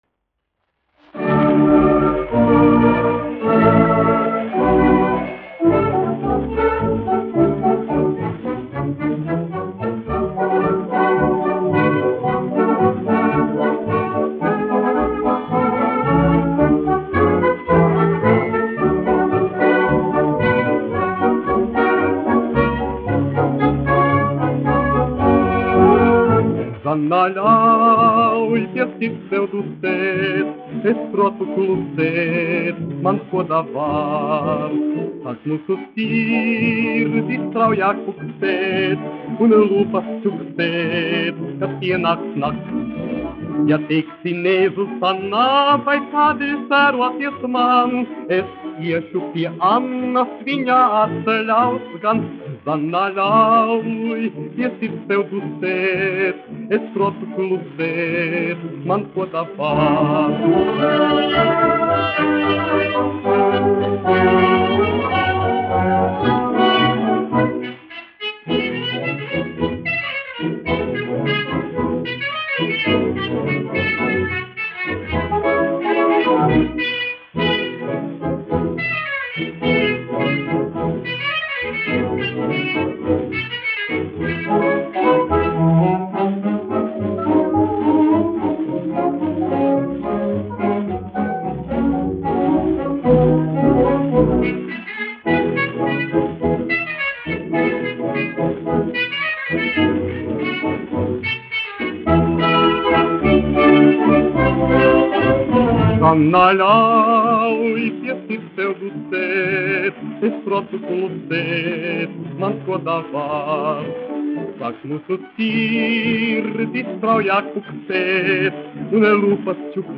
1 skpl. : analogs, 78 apgr/min, mono ; 25 cm
Populārā mūzika -- Latvija
Fokstroti
Skaņuplate